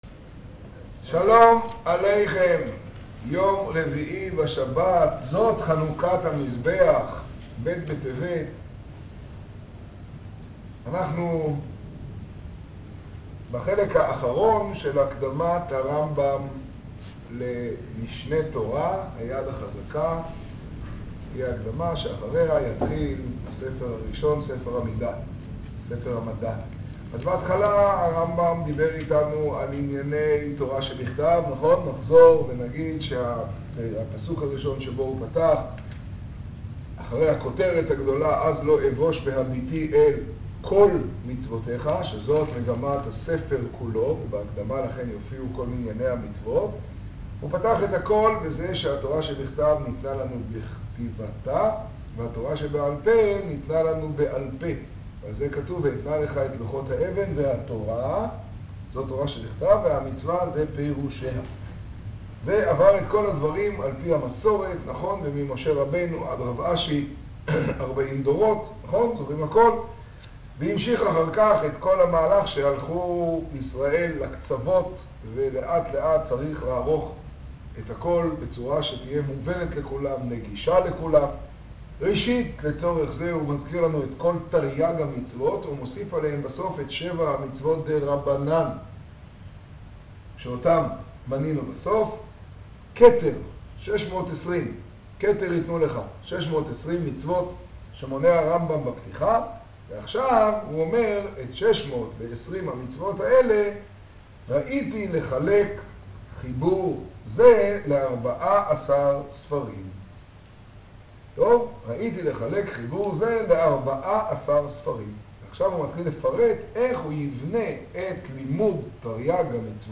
השיעור במגדל, ב טבת תשעה.